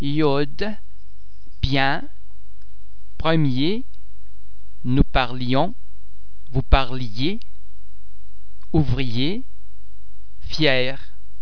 Please be mindful of the fact that all the French sounds are produced with greater facial, throat and other phonatory muscle tension than any English sound.
The French [ yeah ] sound is very much like the /y/ sound in the English words yes or eye.
·i
ille_iode.mp3